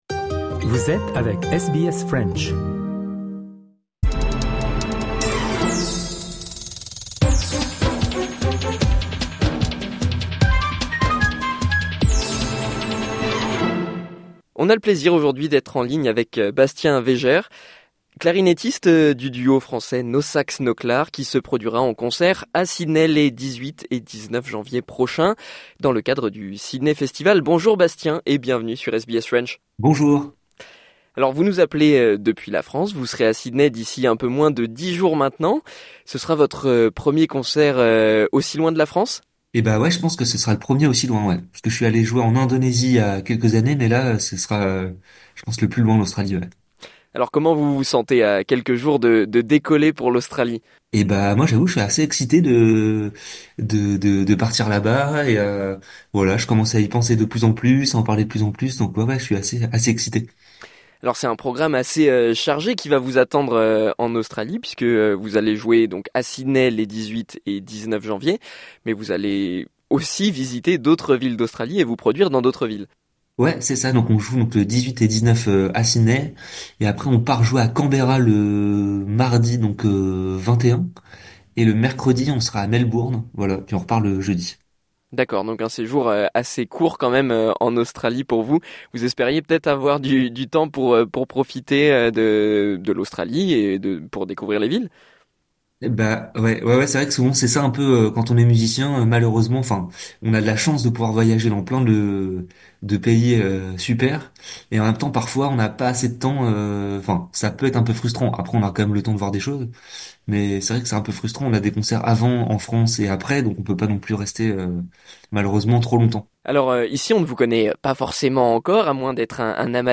ITW NoSax NoClar Share